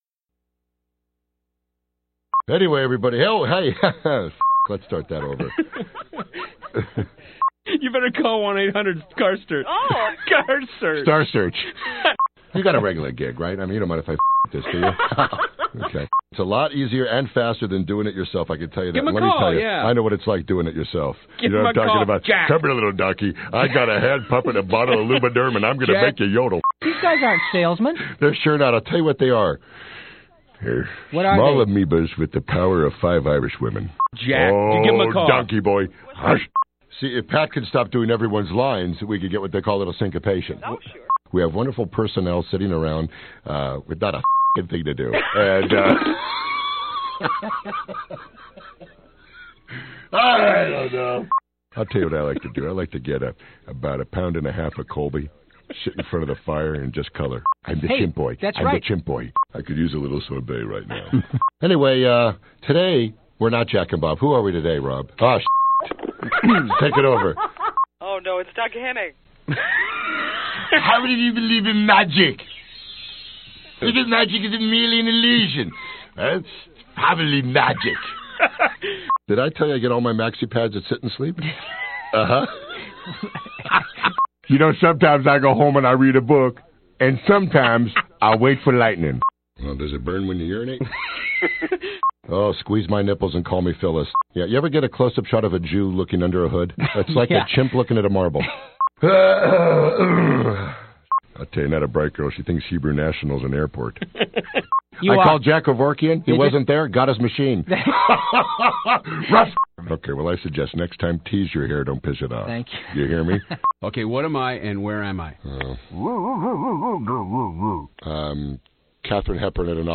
Tags: Celebrities Bloopers Barry White Celebrity bloopers Blooper Audio clips